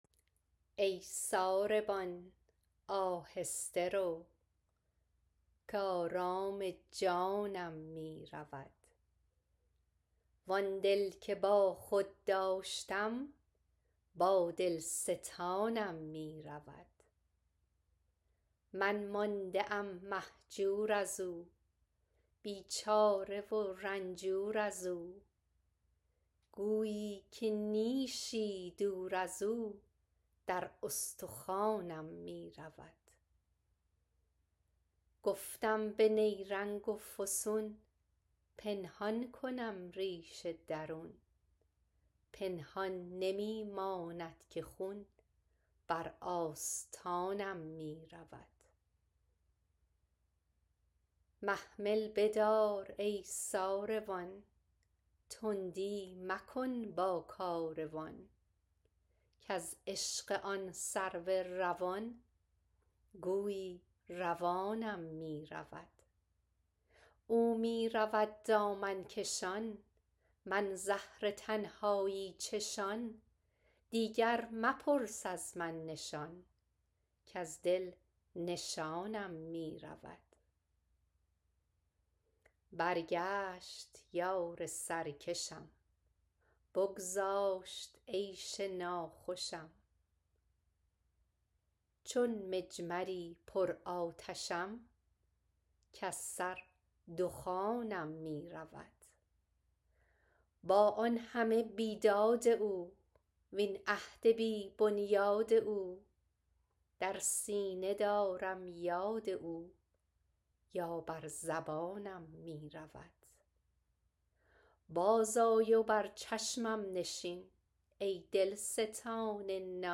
Poem recited